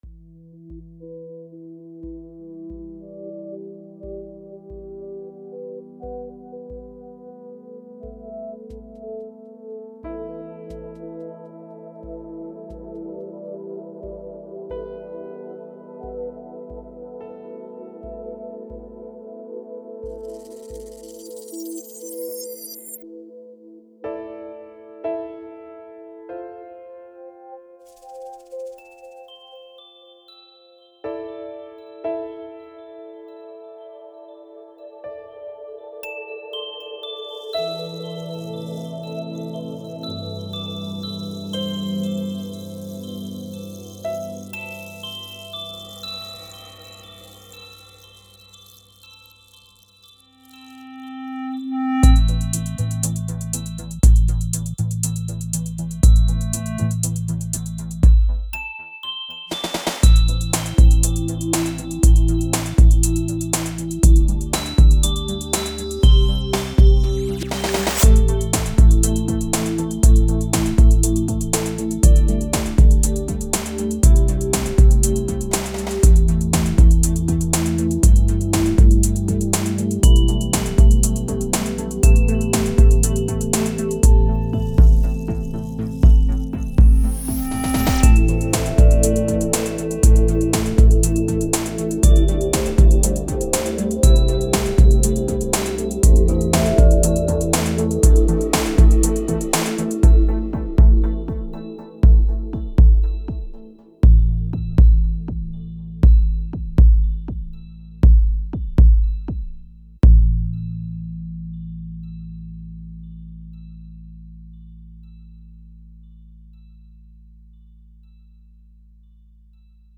Aaaahh... Enfin un beat...
Belle Nuit lounge & posée.
Bon morceau électro.